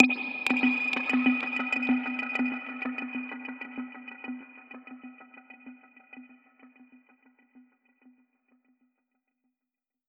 Index of /musicradar/dub-percussion-samples/95bpm
DPFX_PercHit_D_95-03.wav